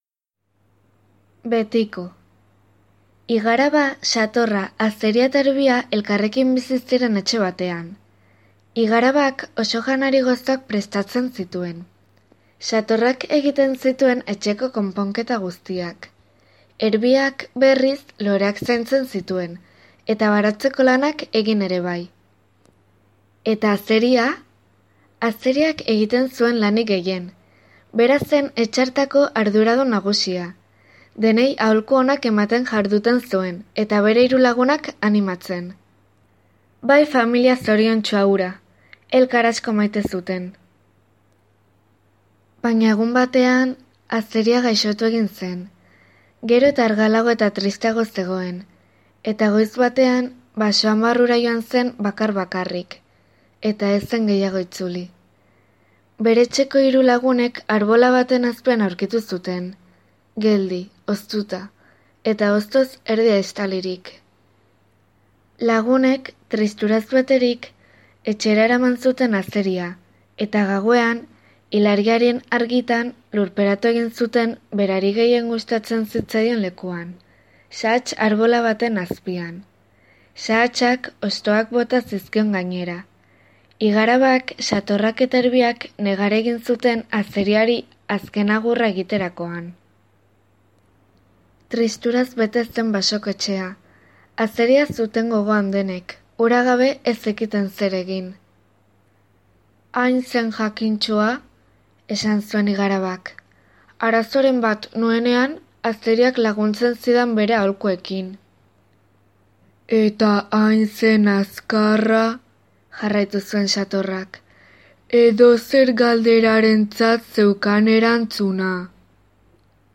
ipuin-kontaketa